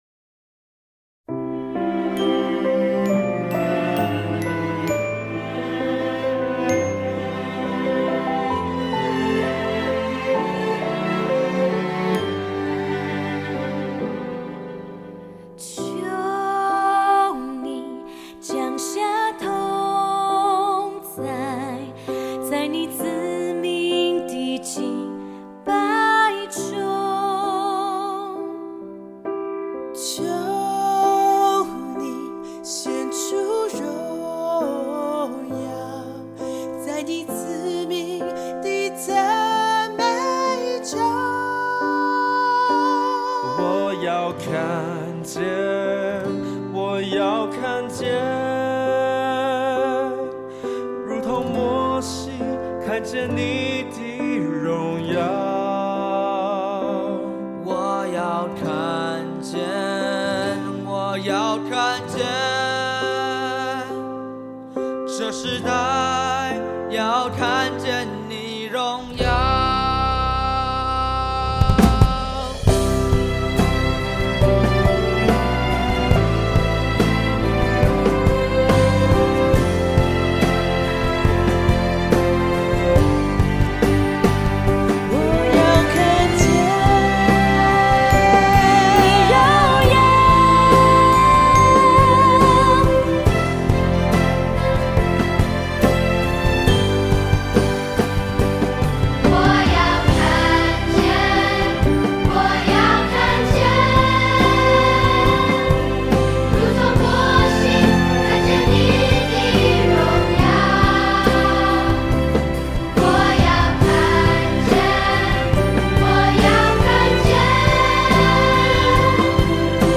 跟唱建议 动作跟唱，有视频和音频两部分。